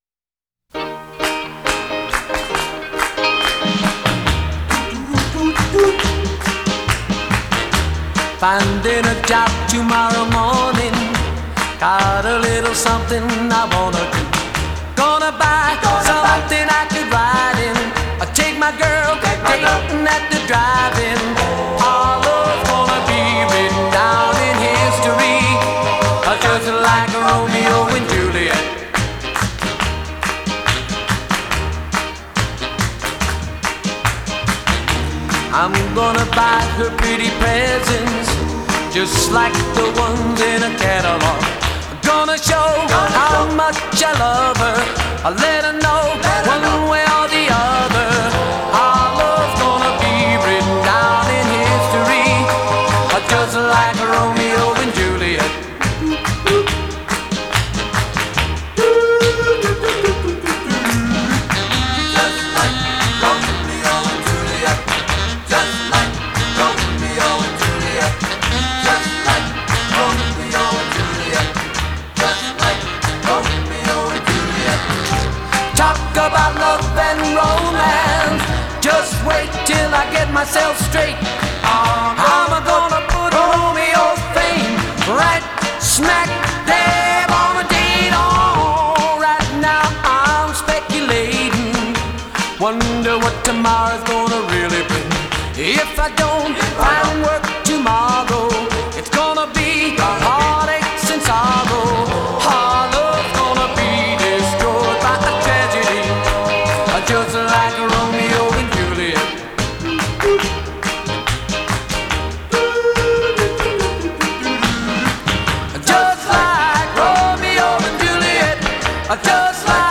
This is the rare stereo mix.